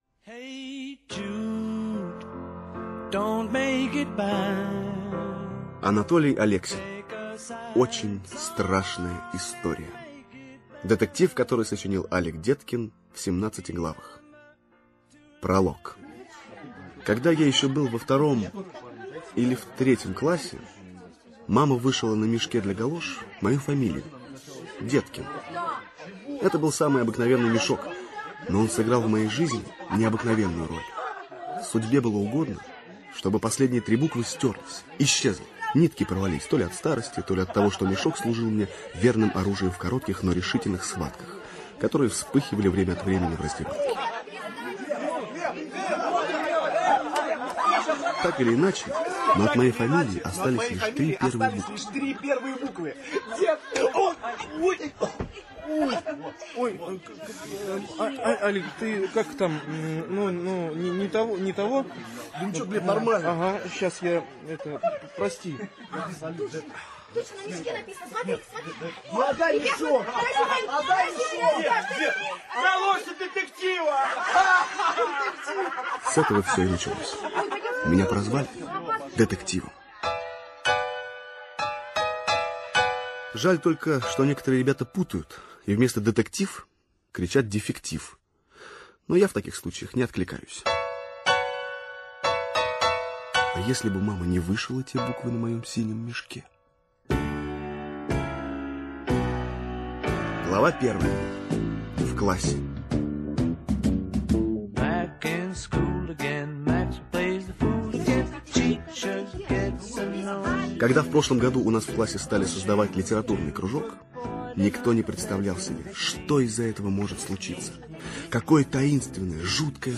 Очень страшная история - аудио повесть Алексина - слушать